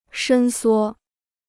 伸缩 (shēn suō): to lengthen and shorten; flexible.